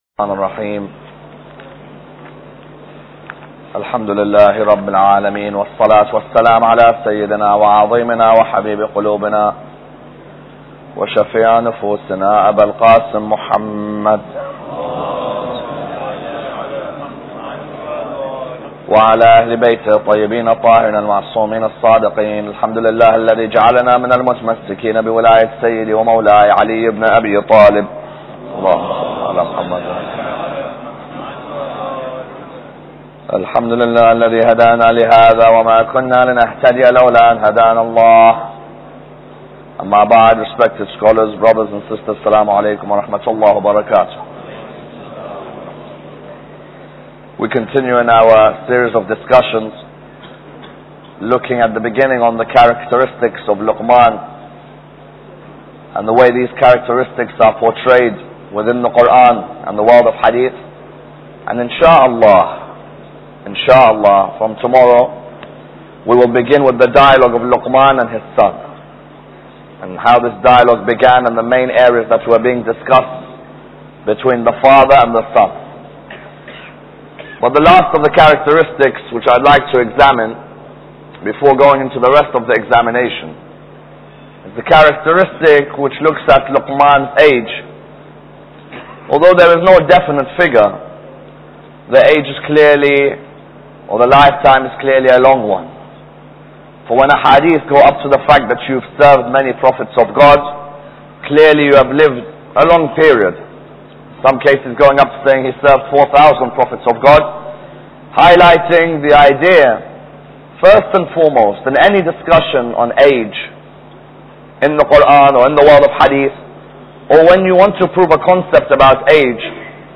Lecture 5